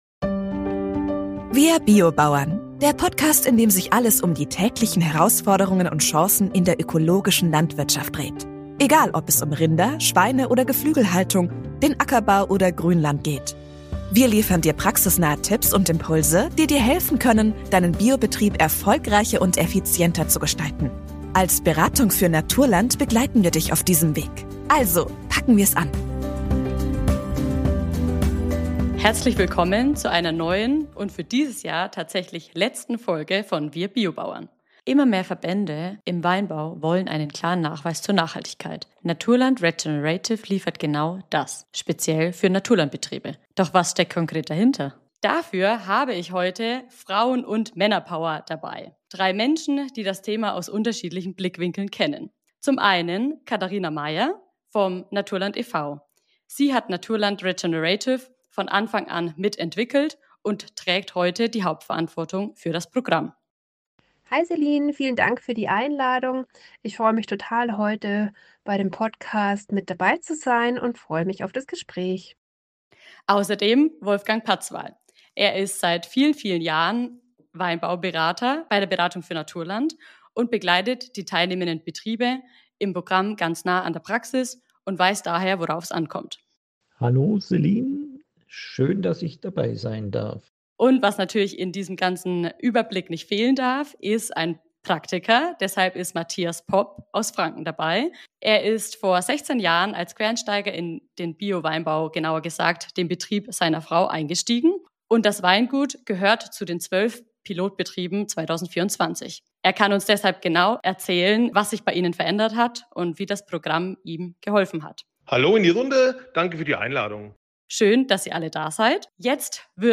In dieser Folge von WIR BIO-BAUERN sprechen wir mit drei Gästen, die das neue Programm Naturland Regenerative aus ganz unterschiedlichen Blickwinkeln kennen: